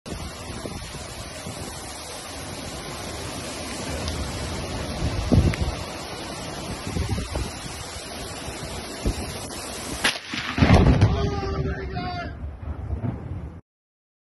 Man Captures Moment ‘too Close For Comfort’ Lightning Strike Hits Home